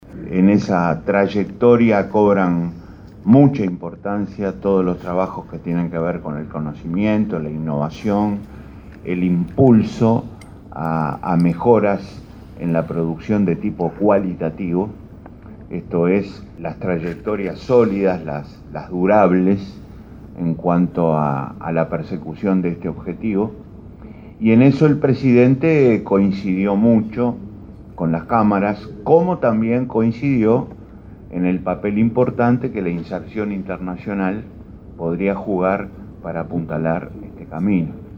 En la conferencia de prensa, luego del Consejo de Ministros que se está encargando del armado del presupuesto quinquenal, Astori dijo que el programa del Frente Amplio (FA) dice que se va a tender al 6 % del PBI a la educación.